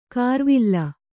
♪ kārvilla